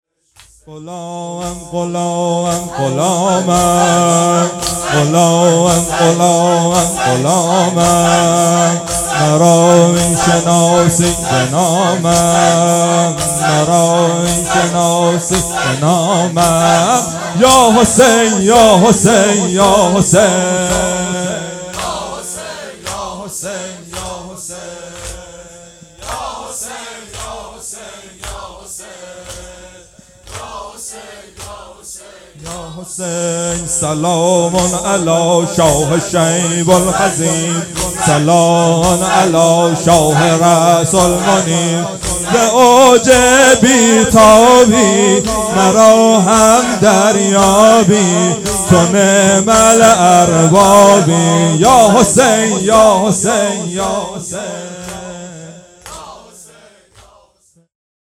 اقامه عزای شهادت حضرت زهرا سلام الله علیها _ دهه دوم فاطمیه _ شب اول